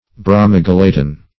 Search Result for " bromogelatin" : The Collaborative International Dictionary of English v.0.48: Bromogelatin \Bro`mo*gel"a*tin\, a. [Bromine + gelatin.]
bromogelatin.mp3